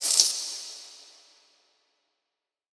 SOUTHSIDE_percussion_it_dropped.wav